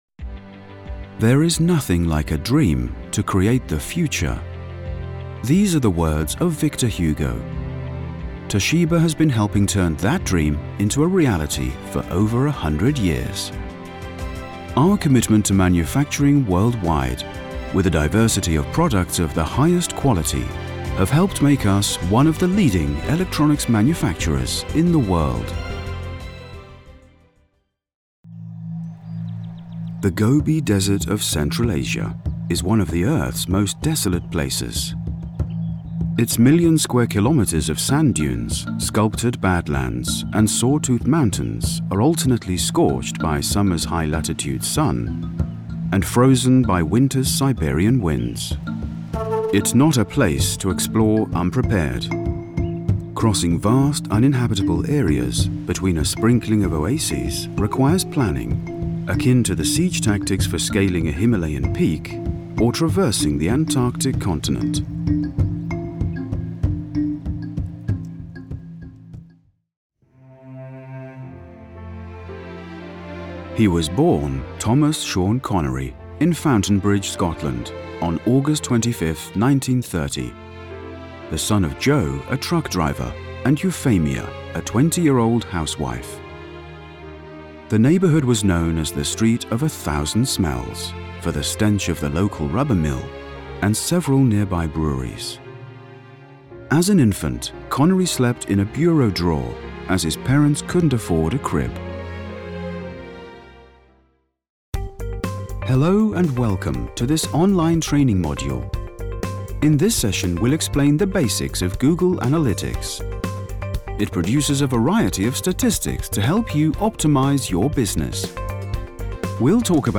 Engels (Brits)
Diep, Natuurlijk, Vertrouwd, Vriendelijk, Warm
Corporate